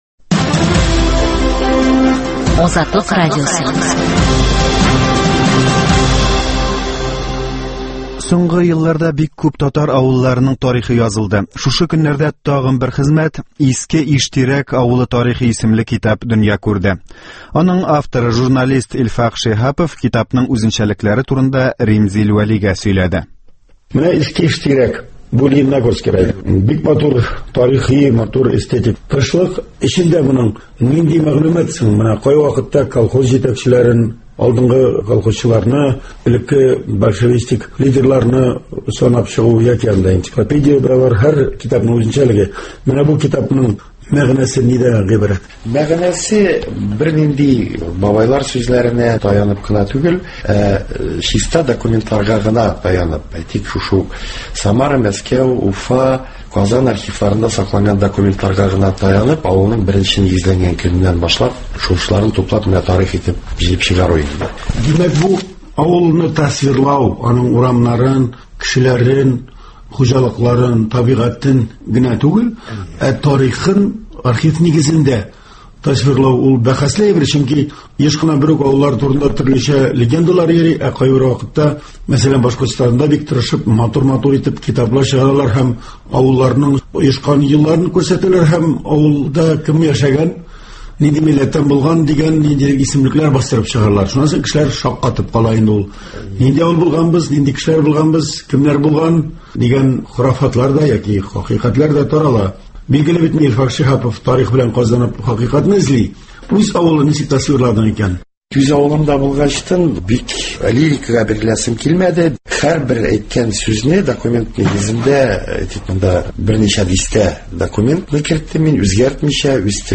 китапның үзенчәлекләре турында “Азатлык” студиясенә килеп сөйләде.